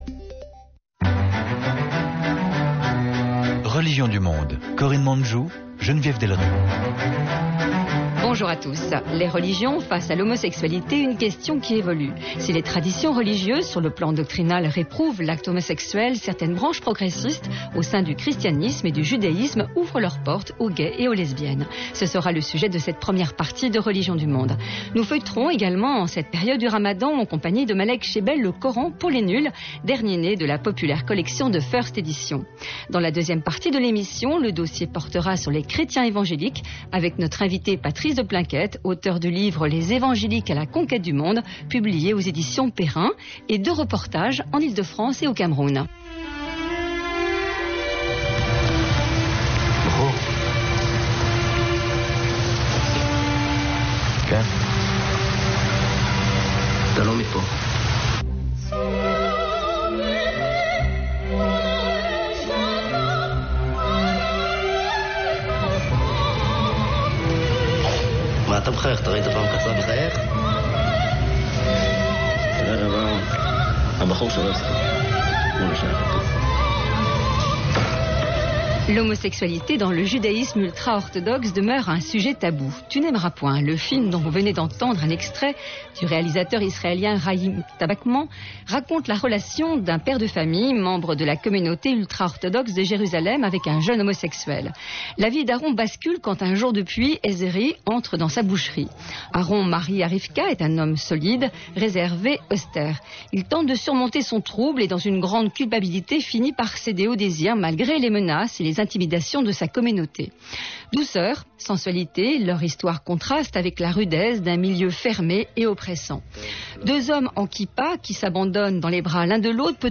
Malek Chebel, Le coran pour les nuls . Conférence de presse de l'auteur sur son livre, et réactions sur autres thèmes d'actualité tels que la Burqa et le ramadan.